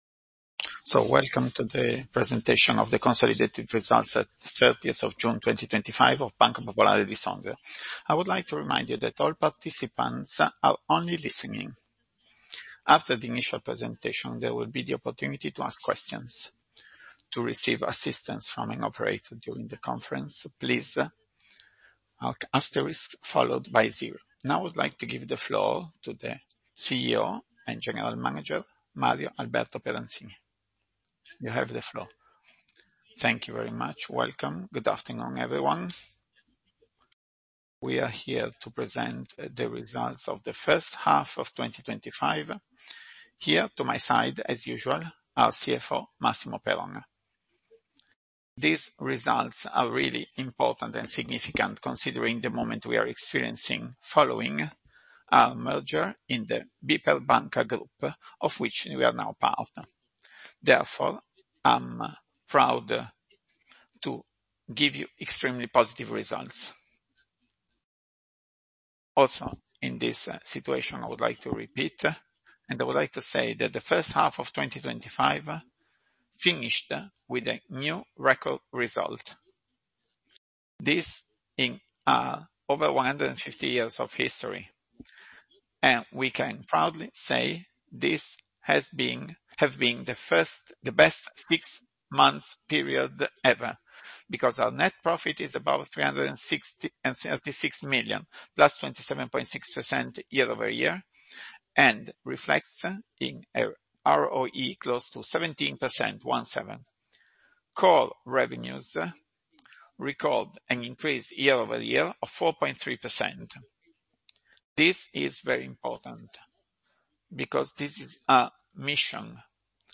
1H Conference call